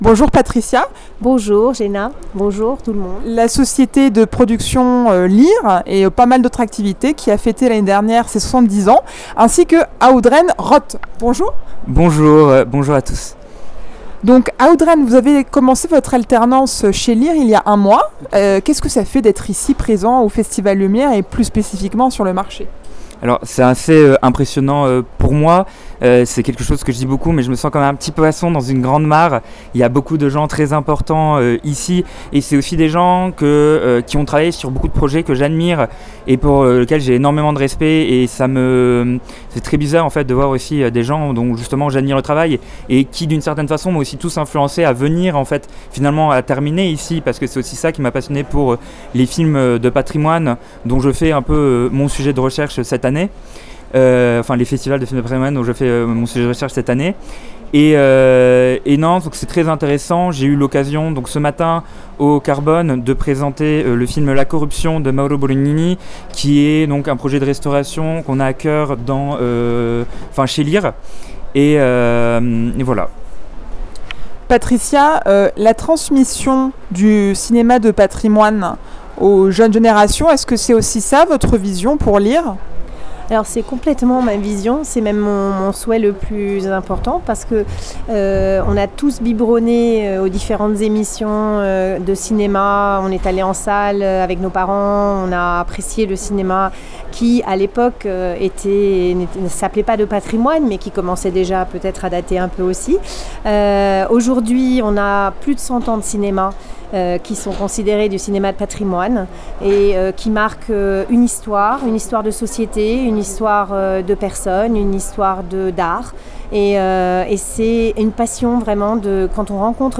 Au marché du film de patrimoine dans le cadre du festival Lumière